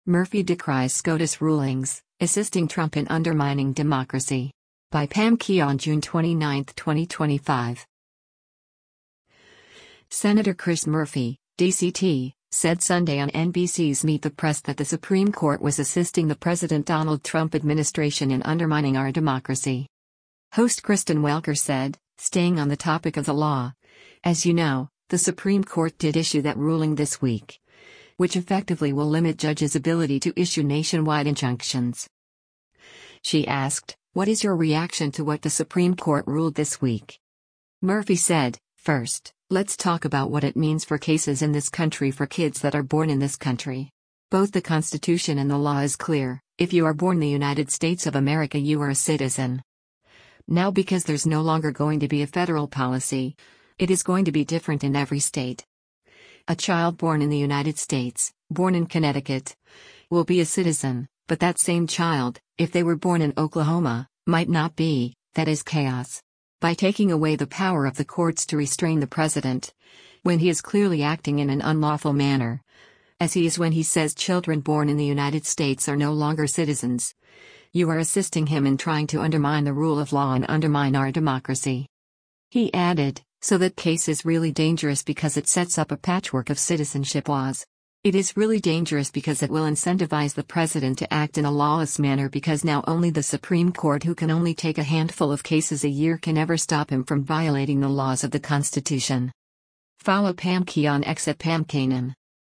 Senator Chris Murphy (D-CT) said Sunday on NBC’s “Meet the Press” that the Supreme Court was “assisting” the President Donald Trump administration in undermining our democracy.